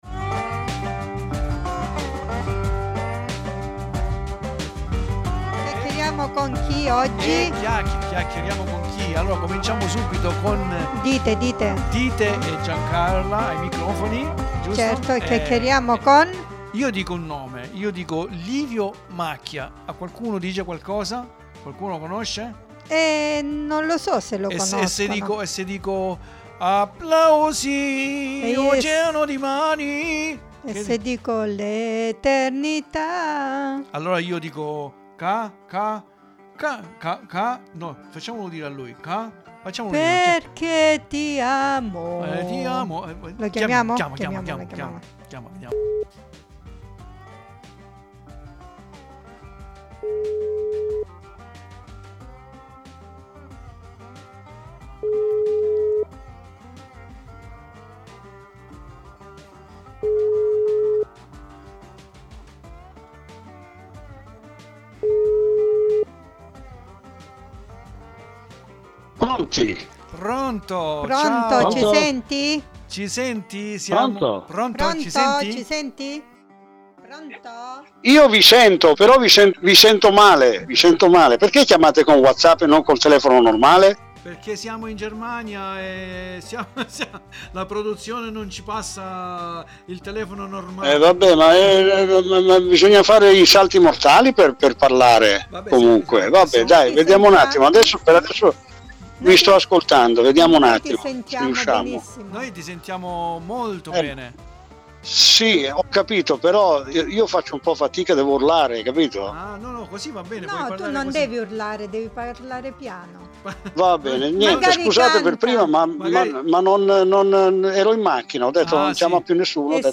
interview LIVIO MACCHIA dei Camaleonti
72025_Livio_Macchia_ntervista_web.mp3